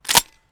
weapon_foley_drop_01.wav